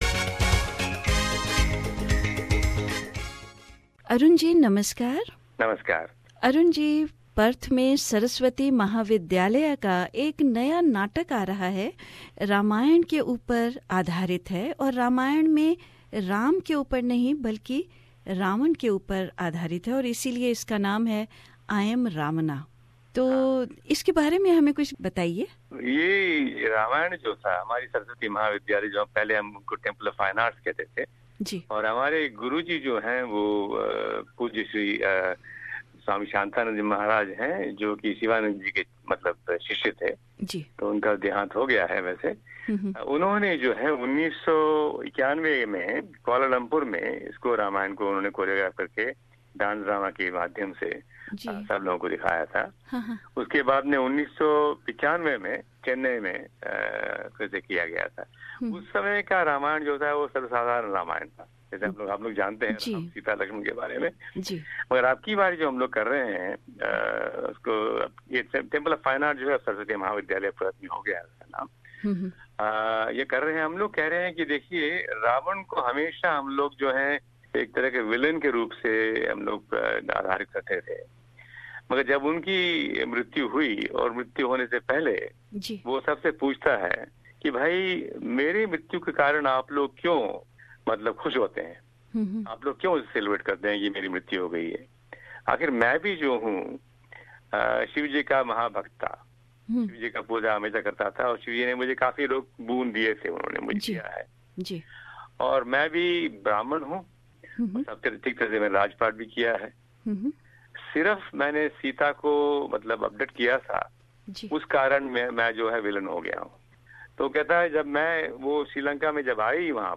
बातचीत